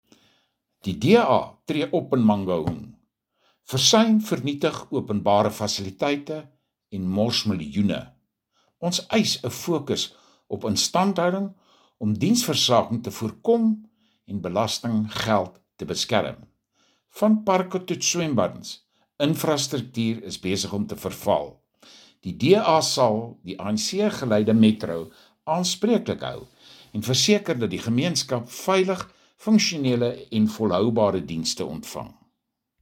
Afrikaans soundbites by Cllr Pieter Lotriet and